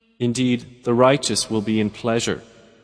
متن، ترجمه و قرائت قرآن کریم